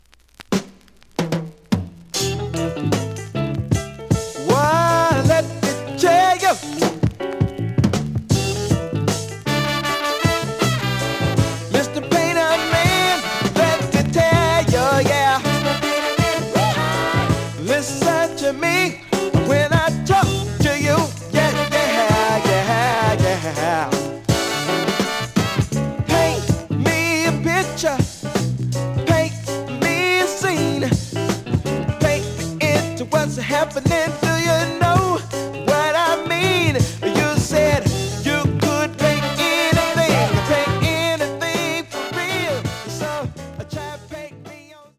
The audio sample is recorded from the actual item.
●Genre: Funk, 70's Funk
Slight damage on both side labels. Plays good.)